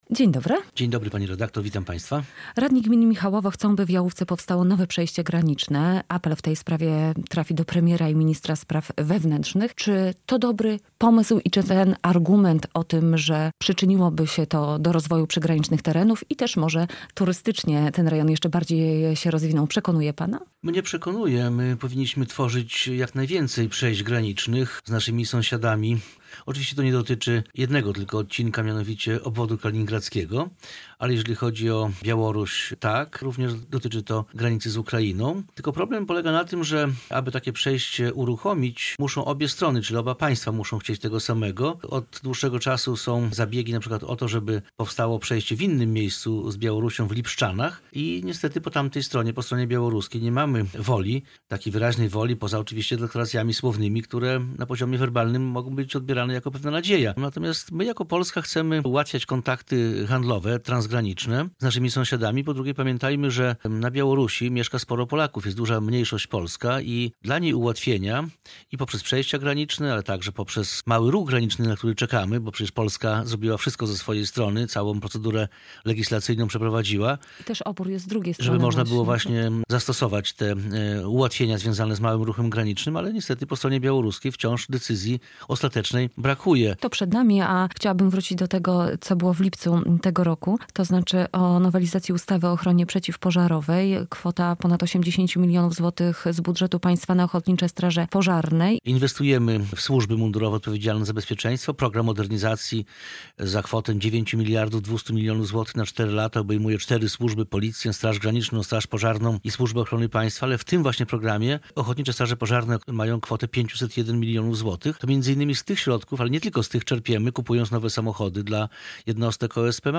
Radio Białystok | Gość | Jarosław Zieliński - wiceminister MSWiA
Tak o pomyśle radnych gminy Michałowo, którzy chcą, by w Jałówce powstało nowe przejście graniczne mówi wiceminister spraw wewnętrznych i administracji Jarosław Zieliński